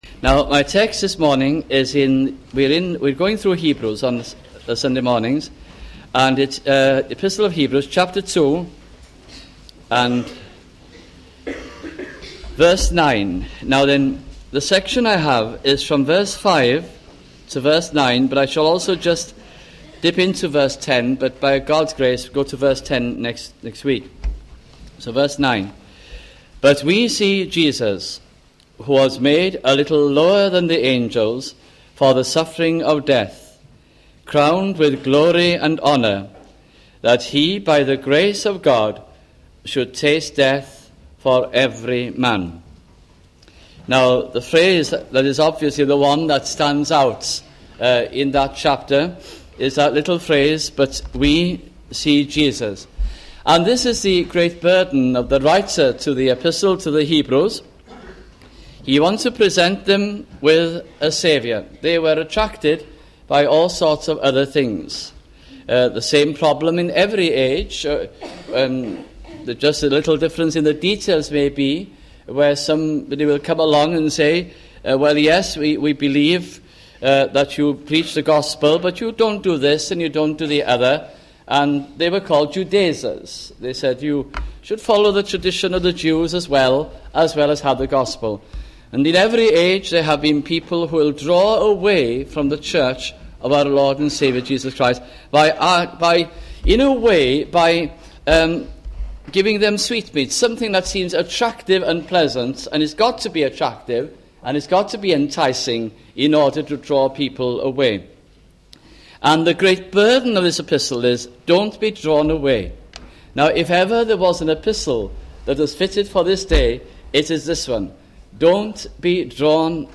» The Epistle to the Hebrews 1984 - 1986 » sunday morning messages